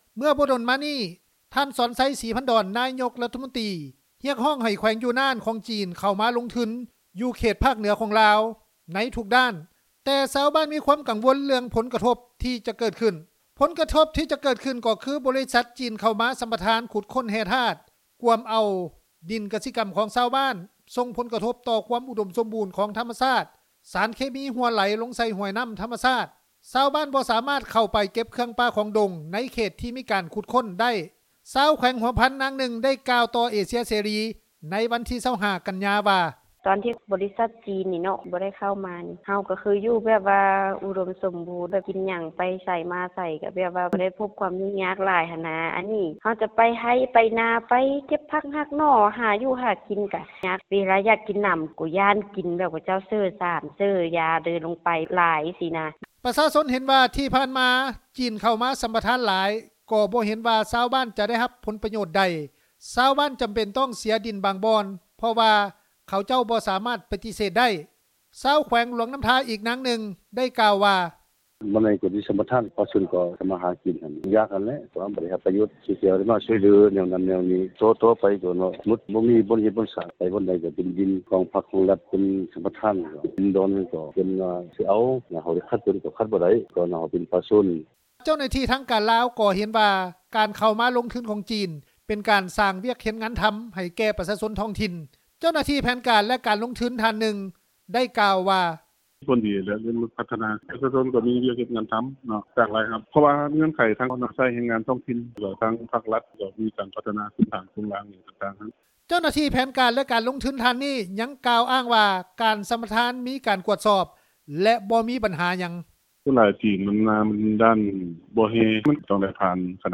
ຊາວແຂວງຫົວພັນ ນາງນຶ່ງ ໄດ້ກ່າວຕໍ່ເອເຊັຽເສຣີ ໃນວັນທີ 25 ກັນຍາ ວ່າ:
ຊາວແຂວງຫລວງນໍ້າທາອີກນາງນຶ່ງ ໄດ້ກ່າວວ່າ:
ເຈົ້າໜ້າທີ່ແຜນການ ແລະ ການລົງທຶນ ທ່ານນຶ່ງ ໄດ້ກ່າວວ່າ: